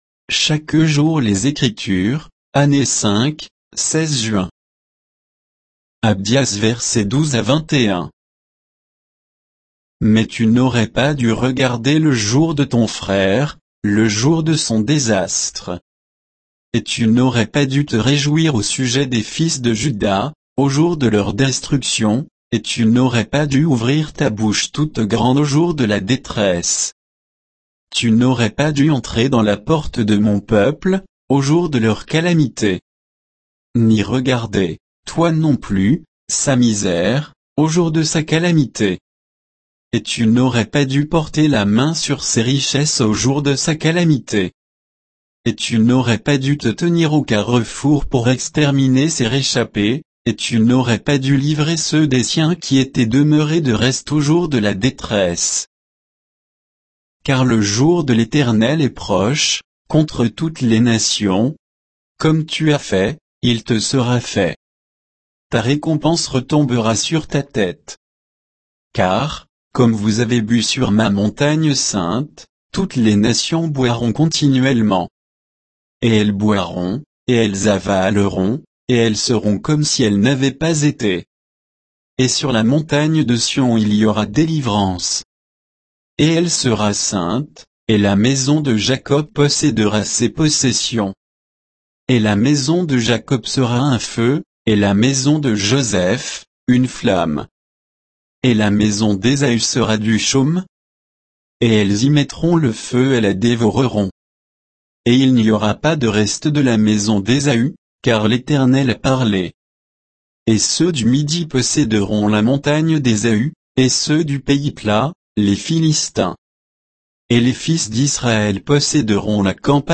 Méditation quoditienne de Chaque jour les Écritures sur Abdias 12 à 21